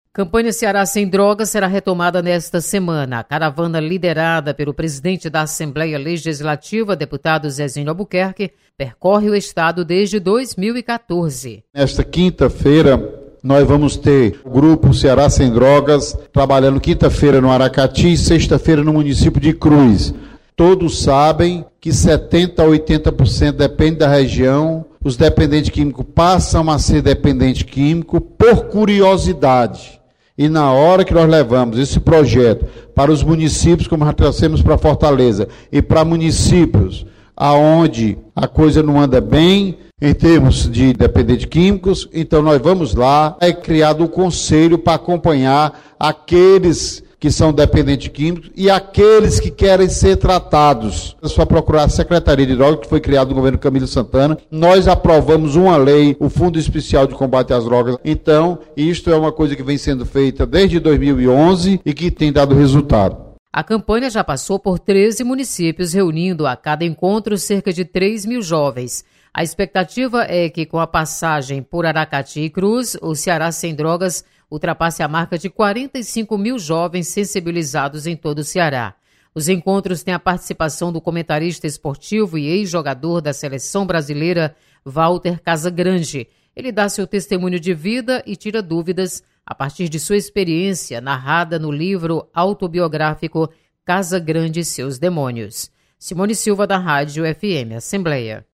Campanha Ceará Sem Drogas será retomada nesta semana. Repórter